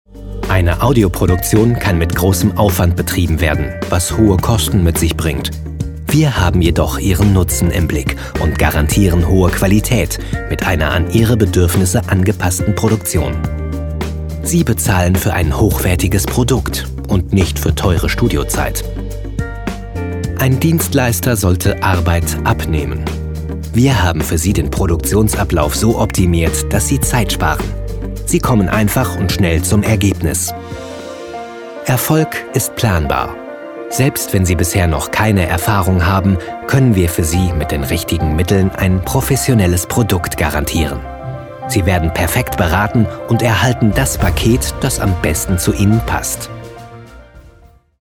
Frische, natürliche Stimme mit einem breiten Spektrum an Emotionen.
deutscher Sprecher. Hörbuch, Werbung, Hörspiel, Kinder, Dokumentation, E-Learnung, Voiceover, junge Stimme
Kein Dialekt
Sprechprobe: Industrie (Muttersprache):